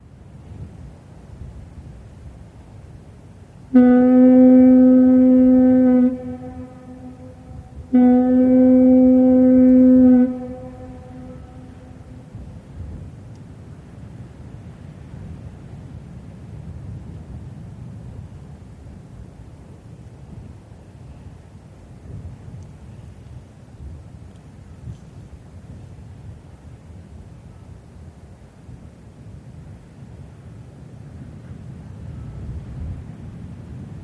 Harbor Ambience Fog Horn and Light Harbor Movement